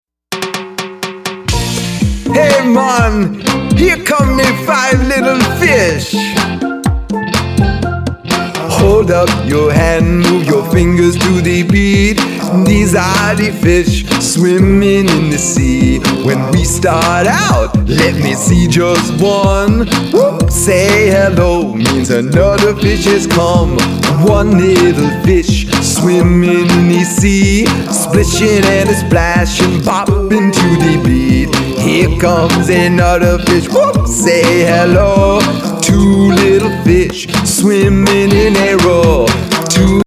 Caribbean